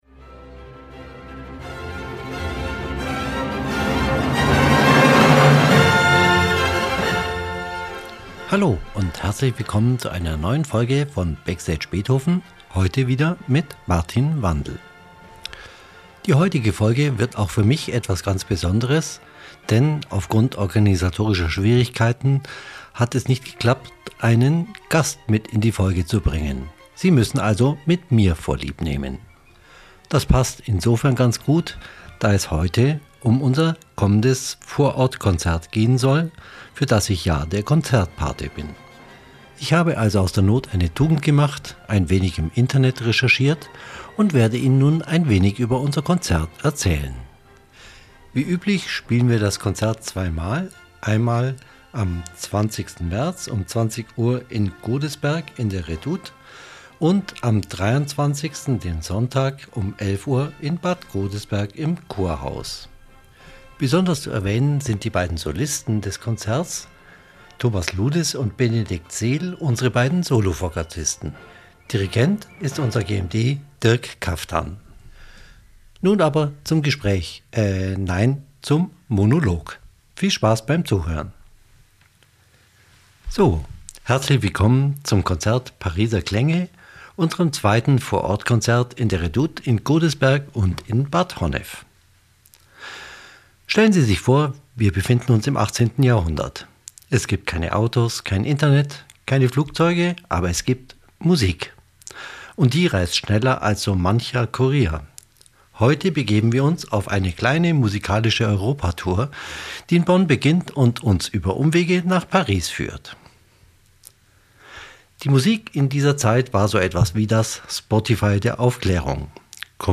In dieser Folge nehmen wir Sie mit zu unseren beiden »Vor Ort Konzerten«, welche die Verbindung zwischen Bonn und Paris hörbar machen.
Begleite uns auf eine Reise durch kunstvolle Sinfonien, ein virtuoses Solo-Konzert und mitreißende Orchesterklänge – ein Fest für alle Liebhaber der klassischen Musik!